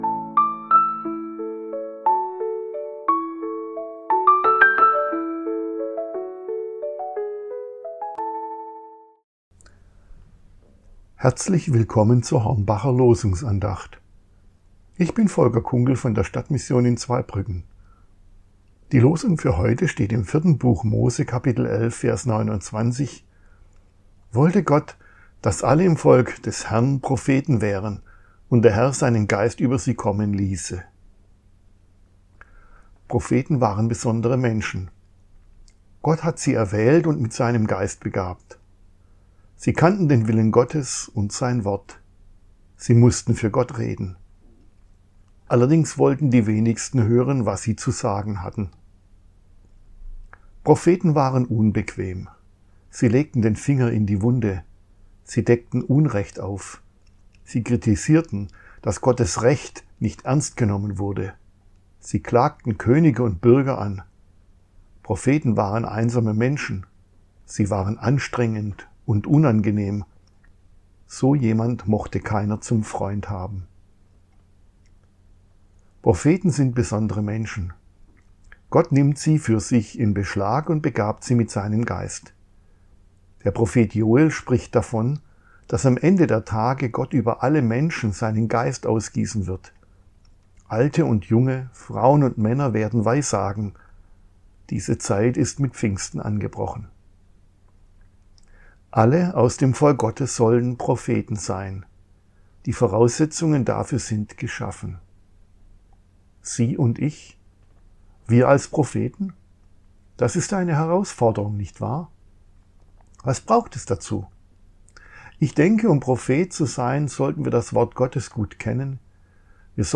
Losungsandacht für Mittwoch, 03.12.2025